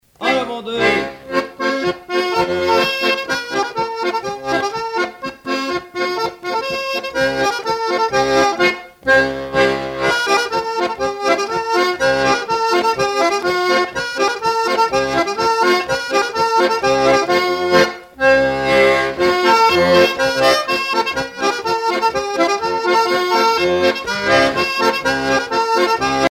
danse : branle : avant-deux ;
Pièce musicale éditée